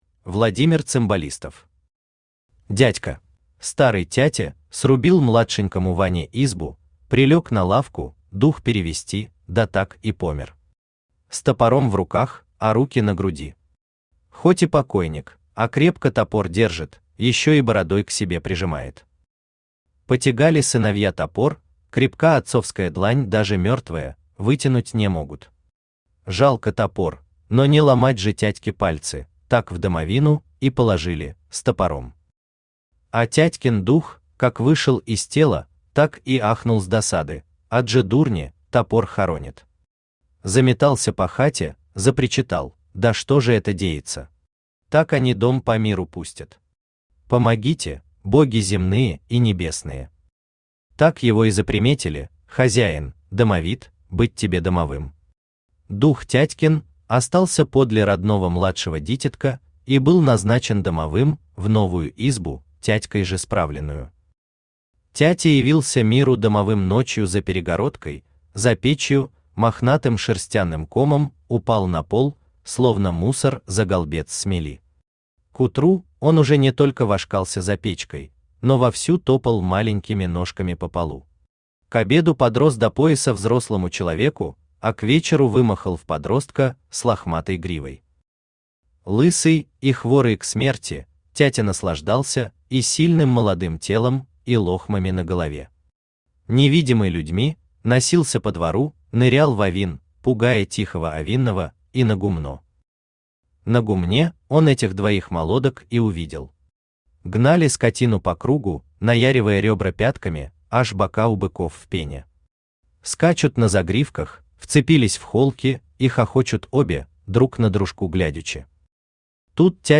Аудиокнига Дядька | Библиотека аудиокниг
Aудиокнига Дядька Автор Владимир Виссарионович Цимбалистов Читает аудиокнигу Авточтец ЛитРес.